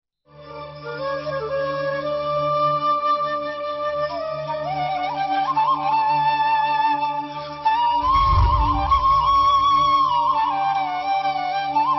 Category: TV Programs / Samaa Tv / Ghamidi kay Saath /
سماء نیوز کے پروگرام غامدی کے ساتھ میں جاوید احمد صاحب غامدی ”آج کی ماں کے مسائل“ سے متعلق سوالات کے جواب دے رہے ہیں